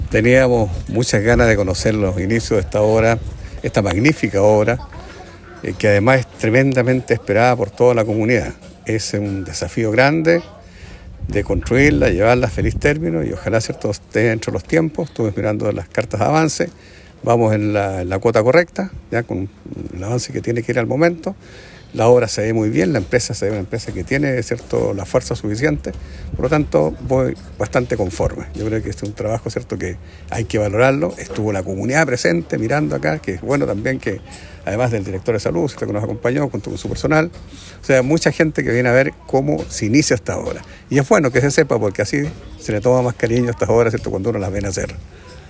Por su parte el Alcalde de Osorno, Jaime Bertin, indicó que esta es una obra muy esperada por toda la comunidad, añadiendo que se espera que pueda ser ejecutada en los plazos entregados por la constructora.